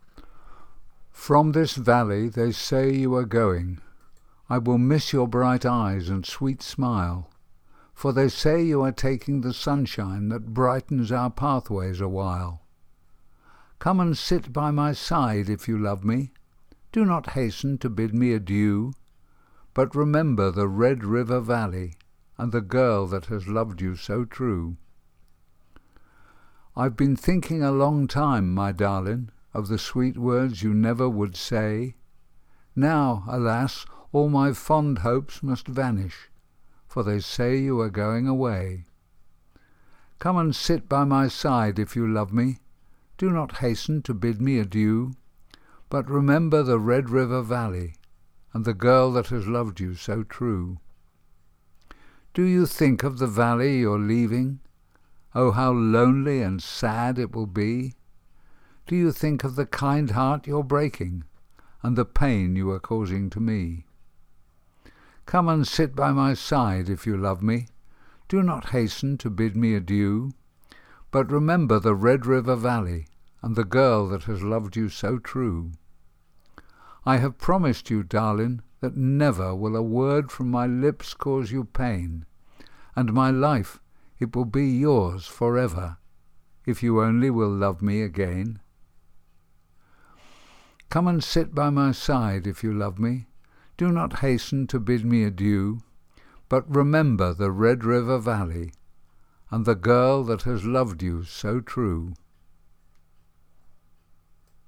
Fichiers de prononciation
Red River Valley Pronunciation.mp3